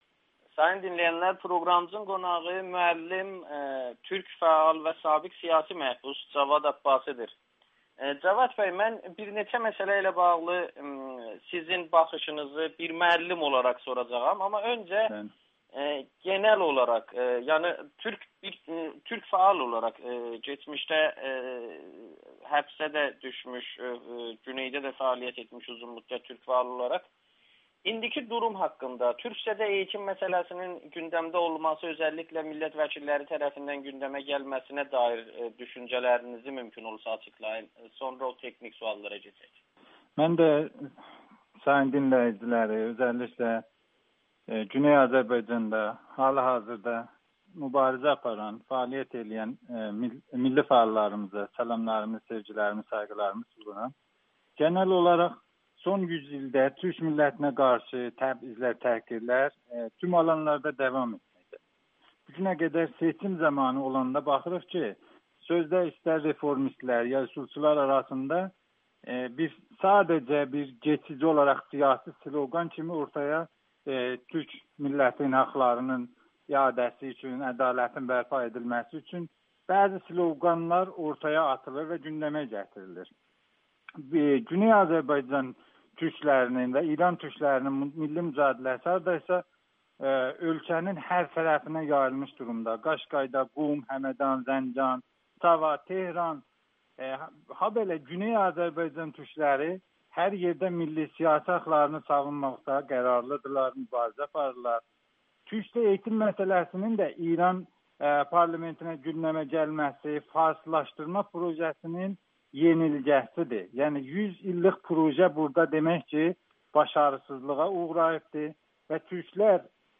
Farslaşdırma siyasəti məğlubiyyətə uğrayır [Audio-Müsahibə]